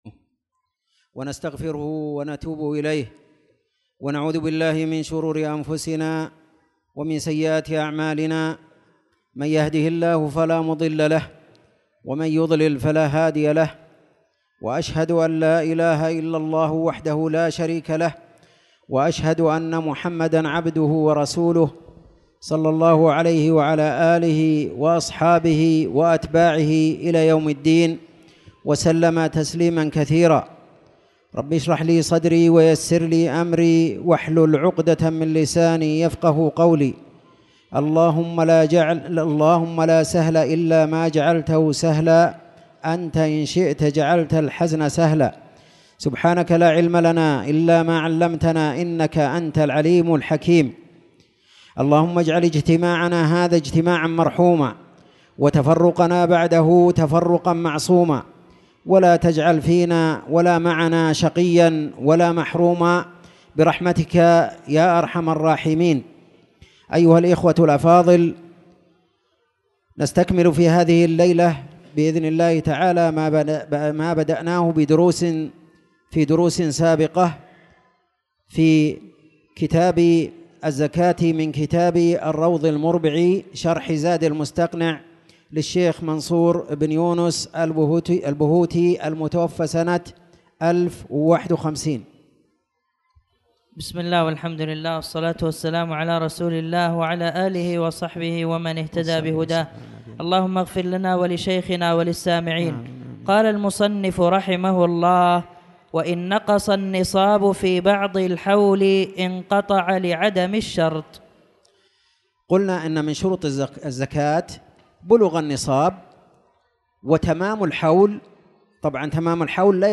تاريخ النشر ٢ شعبان ١٤٣٧ هـ المكان: المسجد الحرام الشيخ